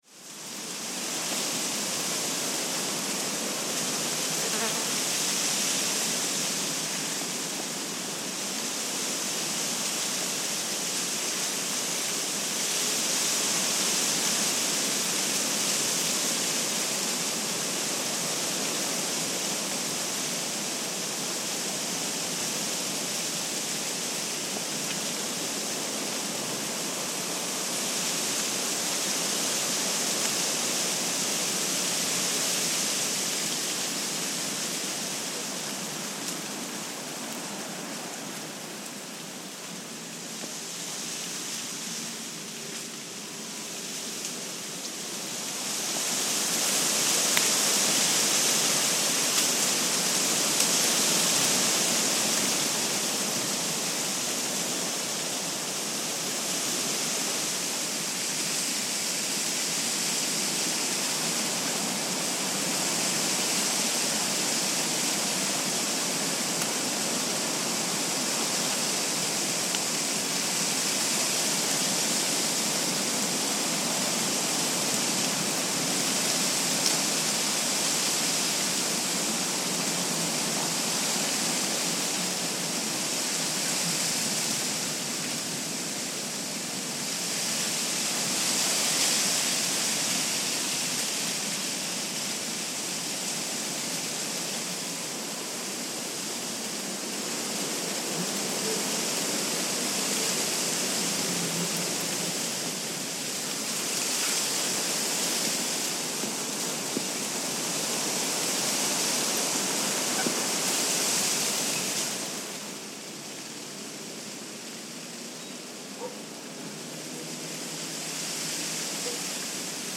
Wind in tabachin trees at Xochicalco pyramids
Stereo 48kHz 24bit.